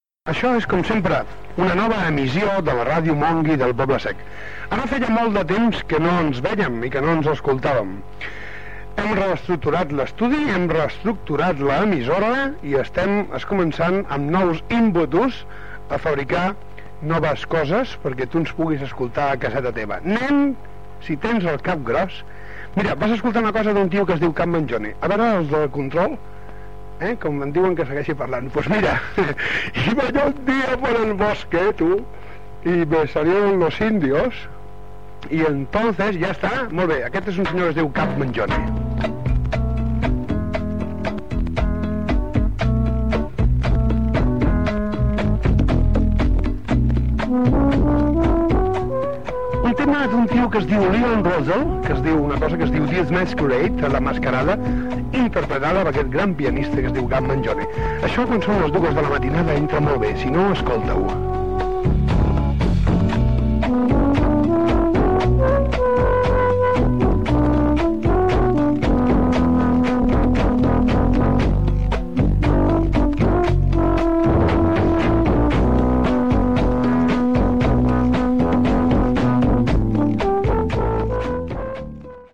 Tema musical Gènere radiofònic Musical